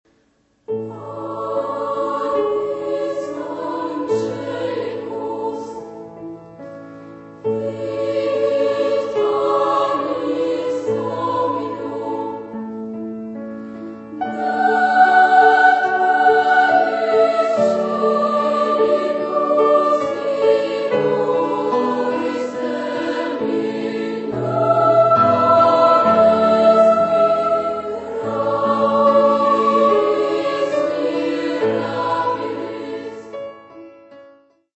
Genre-Stil-Form: geistlich ; Gebet ; Hymnus (geistlich)
Chorgattung: SA  (2 Knabenchor ODER Kinderchor ODER Mädchenchor ODER Frauenchor Stimmen )
Instrumente: Orgel (1) oder Klavier (1)
Tonart(en): Ges-Dur